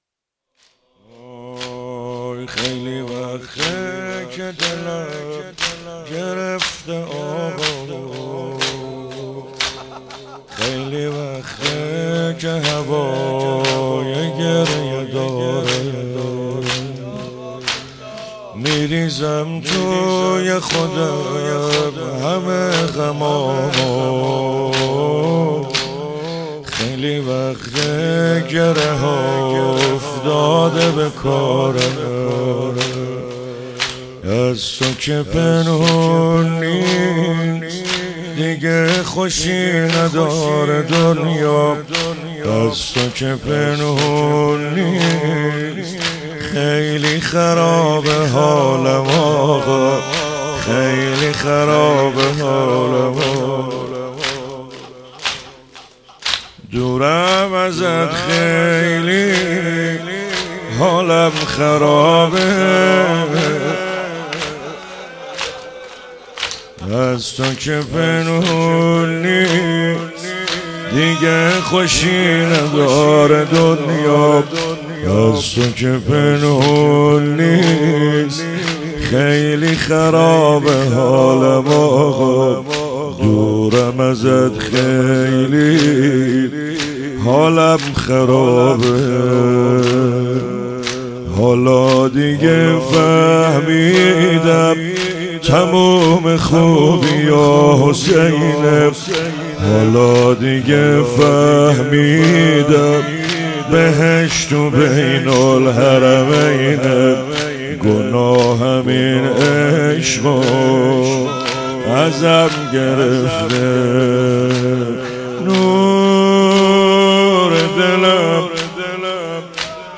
شور، زمینه ترکیبی؛ شورحسینی، امام رضایی، فوق العاده احساسی